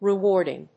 音節re・wárd・ing 発音記号・読み方
/rɪˈwɔrdɪŋ(米国英語), rɪˈwɔ:rdɪŋ(英国英語)/